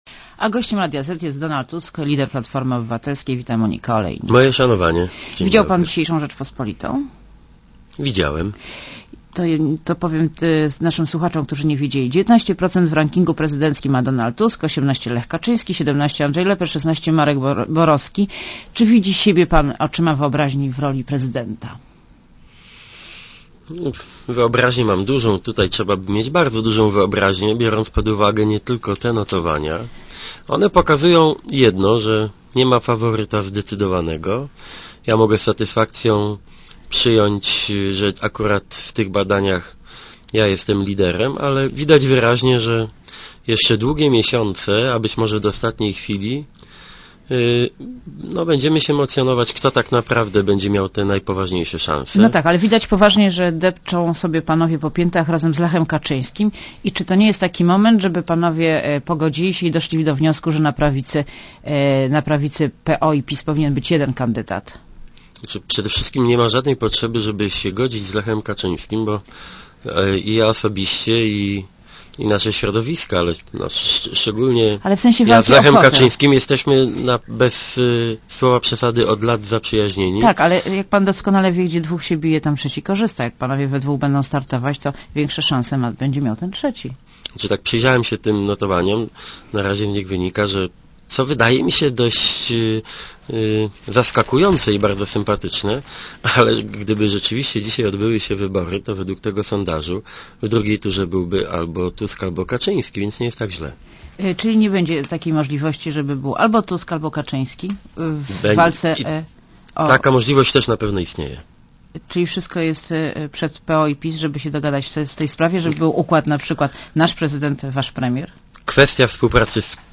* Posłuchaj wywiadu * Gościem Radia Zet jest Donald Tusk , lider Platformy Obywatelskiej.